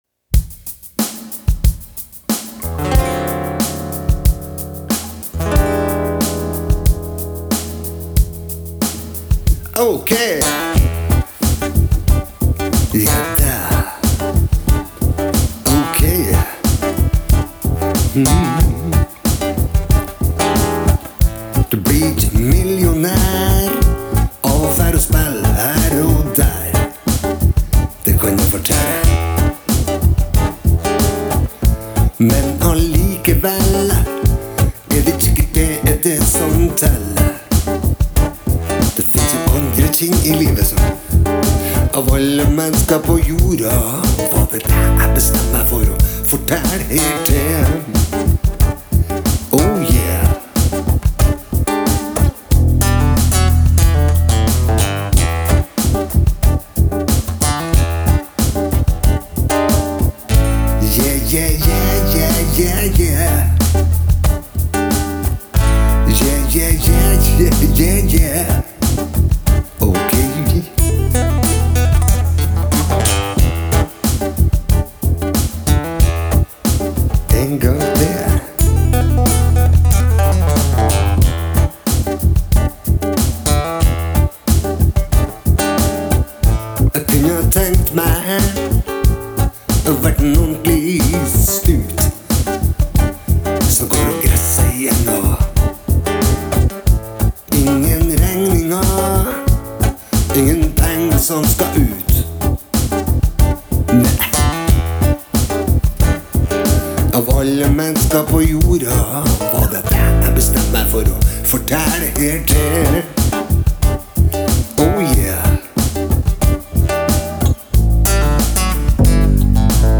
Det kuleste er at jeg spiller på en nylonstrenger!
Ganske kult med bare en trommegroove, syntbass og én kassegitar. Alt er ett take!